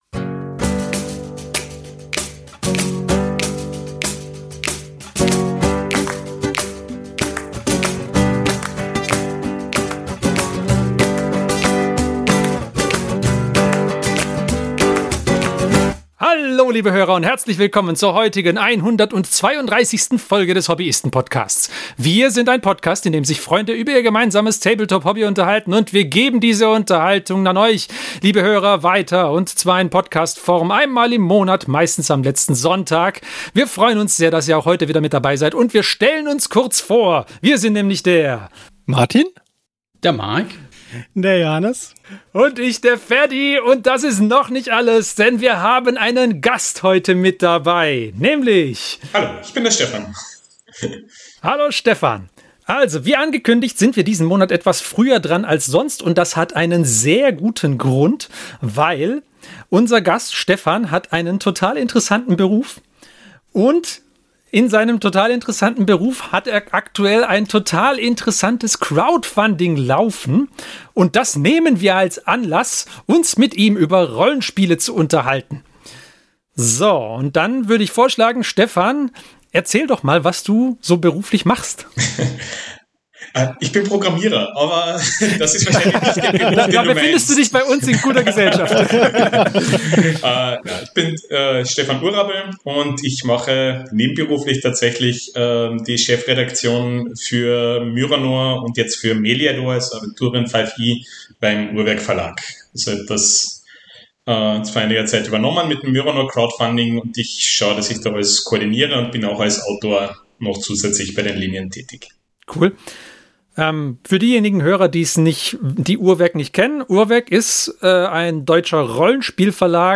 Episode 132 - Aventurien meets DnD 5E - Interview